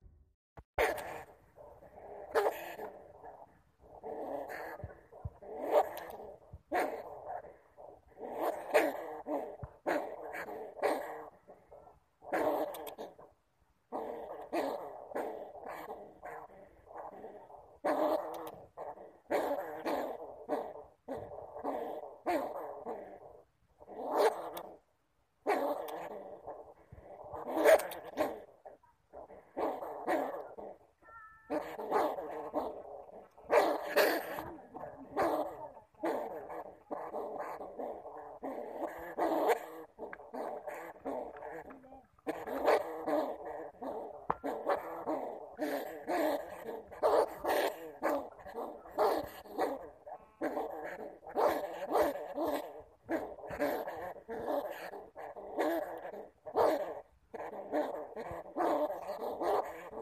Tiếng Dúi kêu MP3